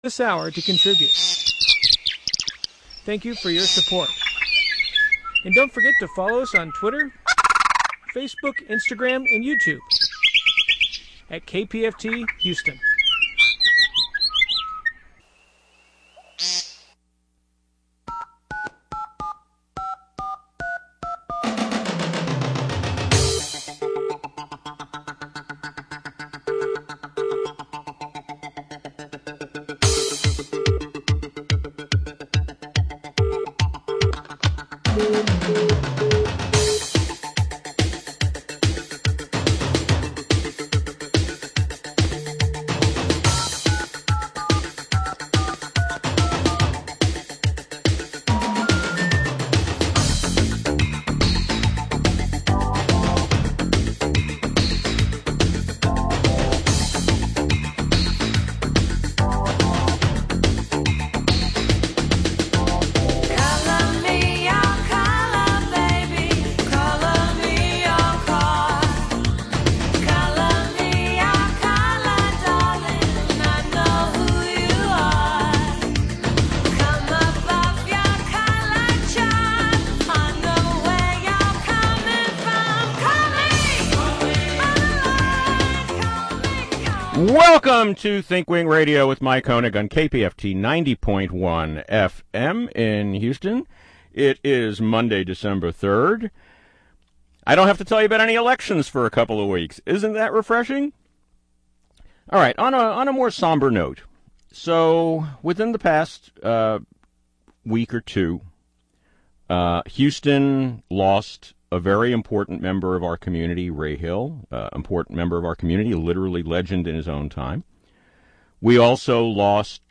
a listener call-in show airing live every Monday night from 2-3 PM (CT) on KPFT-FM 90.1 (Houston).